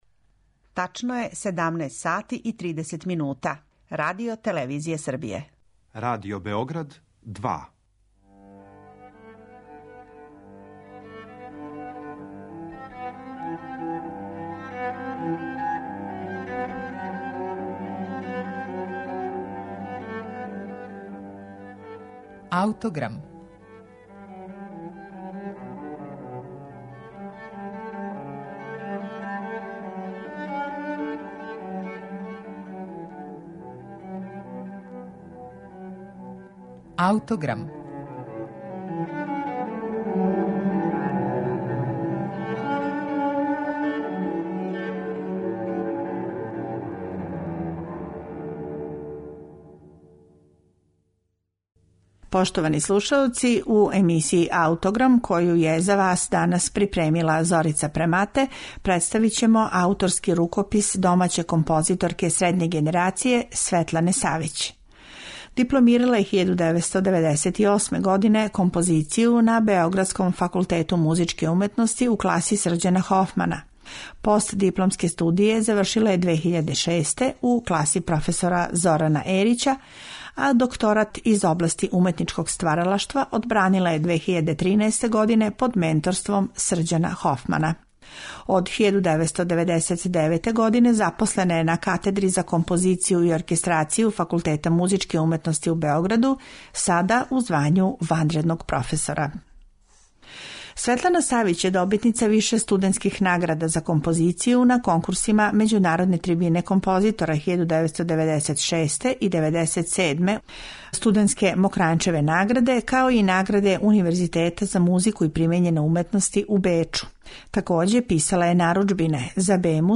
виолончелиста
пијанисткиња
мецосопран